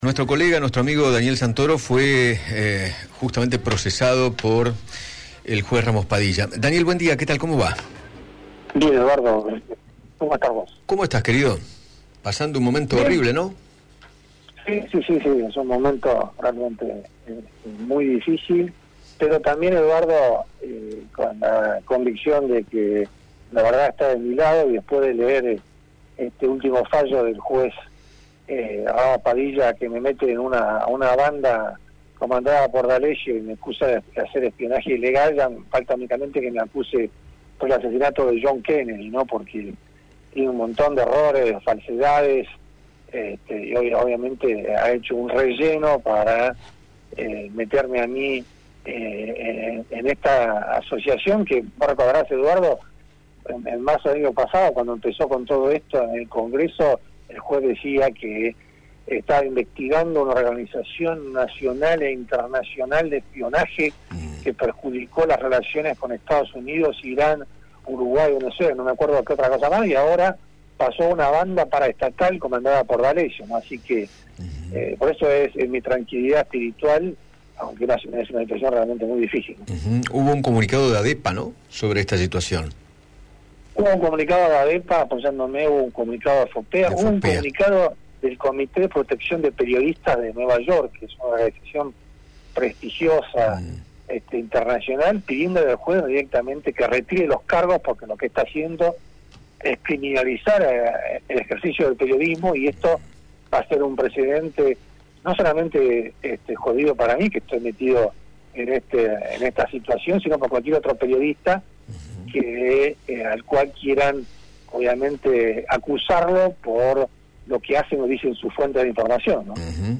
El periodista Daniel Santoro dialogó con Eduardo Feinmann acerca de la ampliación del procesamiento en su contra, realizado por el juez Ramos Padilla, en la causa conocida como caso D´Alessio, en la cual, se investigan presuntas maniobras de espionaje y extorsión.